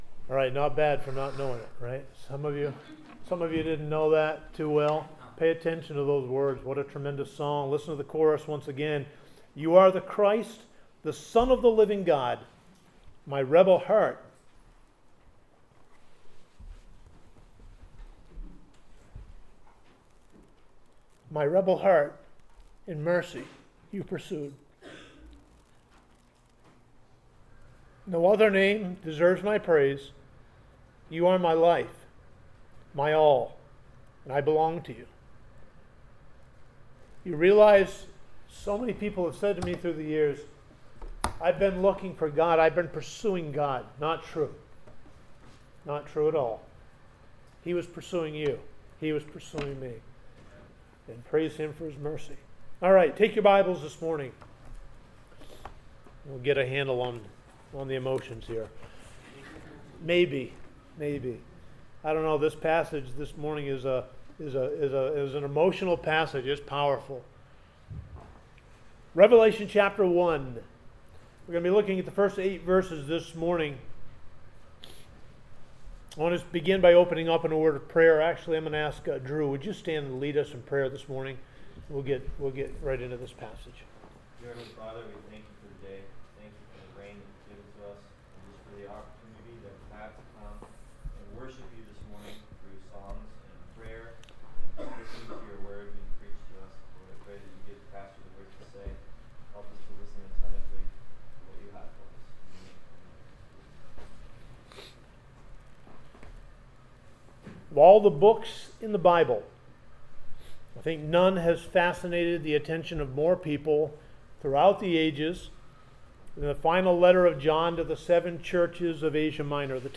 The Revelation of Jesus Christ - South Gardiner Baptist Church